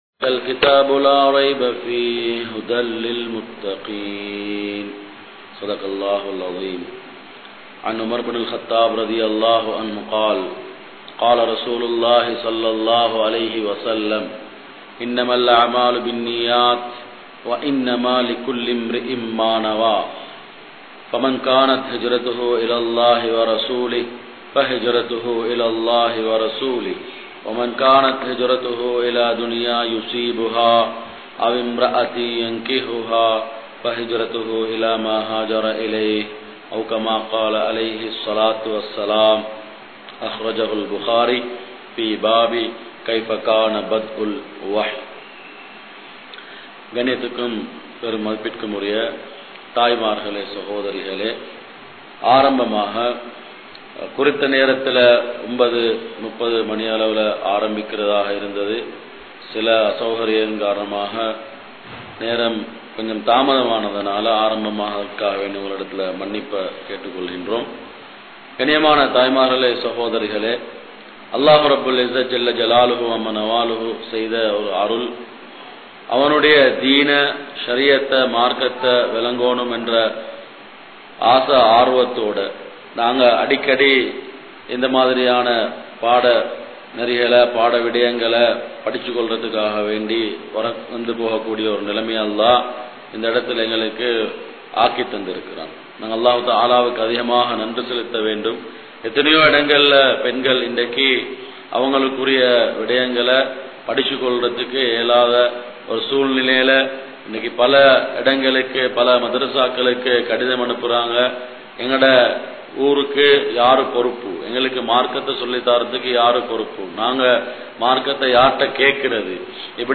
Islam Koorum Pengalin Suththam (இஸ்லாம் கூறும் பெண்களின் சுத்தம்) | Audio Bayans | All Ceylon Muslim Youth Community | Addalaichenai